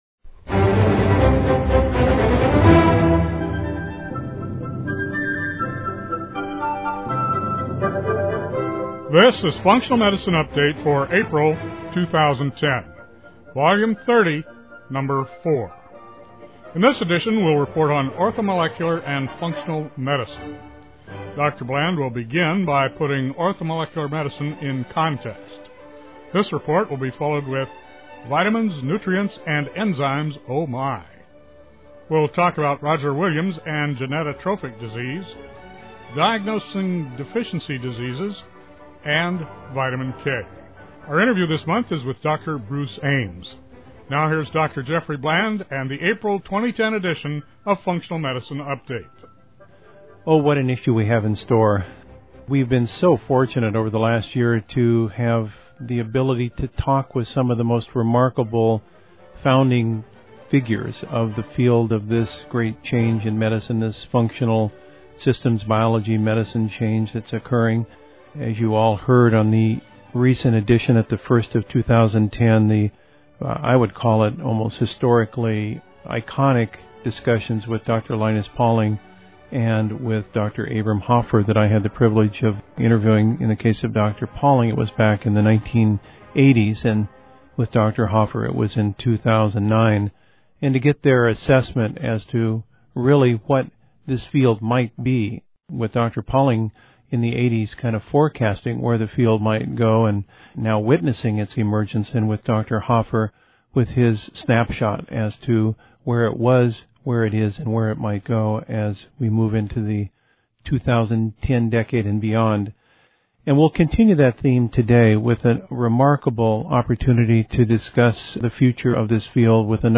We have a remarkable opportunity to discuss the future of this field with another of the iconic founding figures, Dr. Bruce Ames.